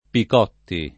[ pik 0 tti ]